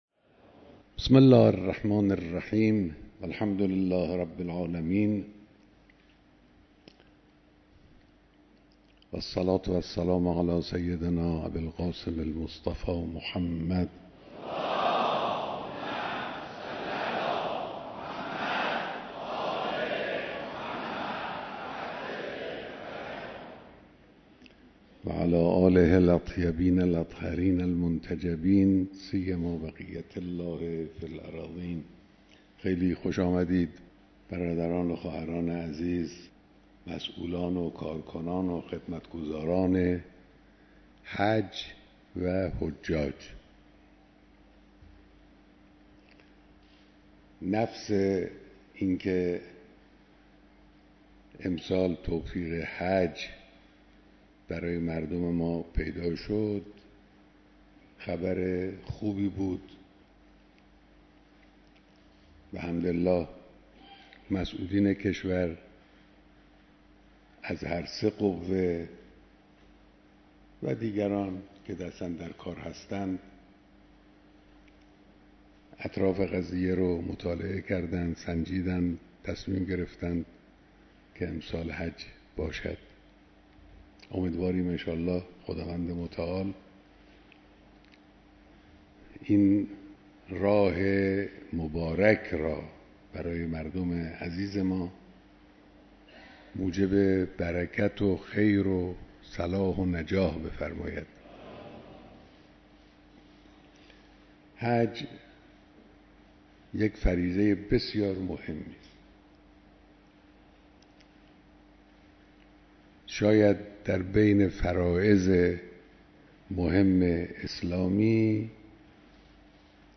بیانات در دیدار مسئولان، کارگزاران و دست‌اندرکاران حج